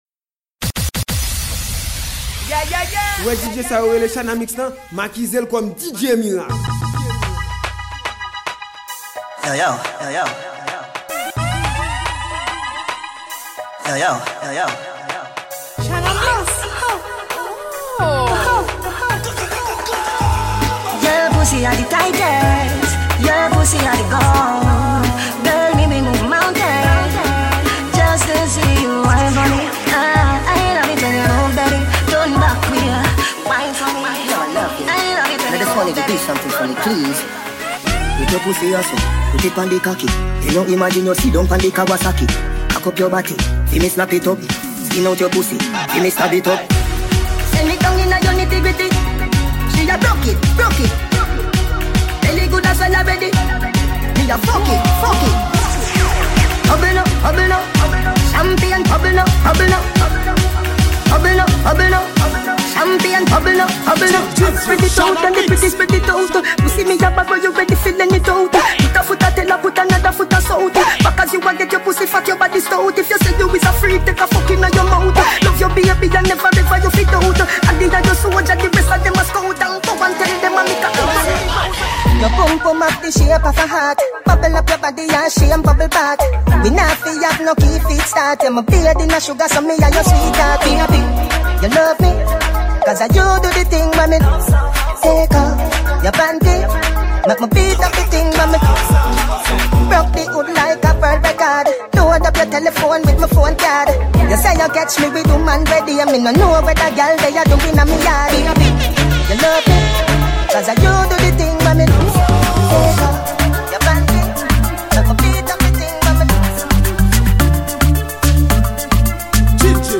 MIXTAPE
Genre: DJ MIXES.